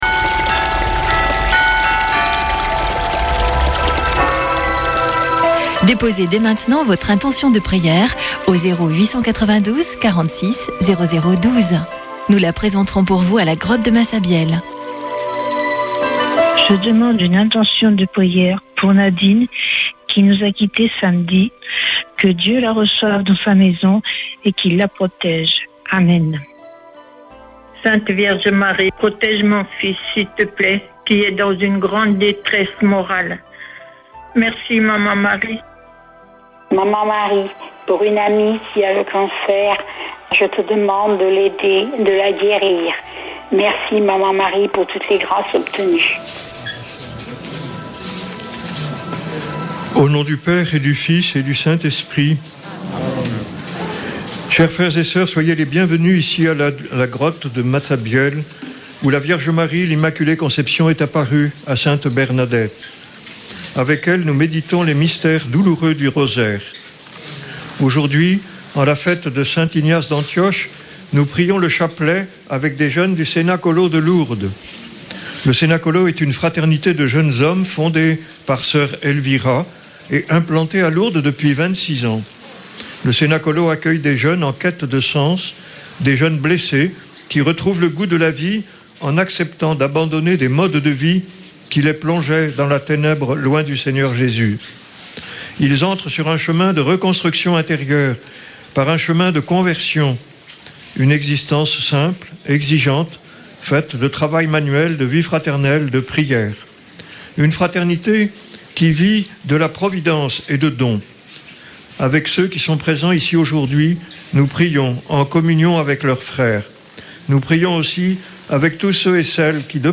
Une émission présentée par Chapelains de Lourdes